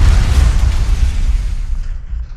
tumbling.wav